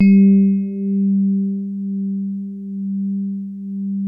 E-PIANO 1
TINE SOFT G2.wav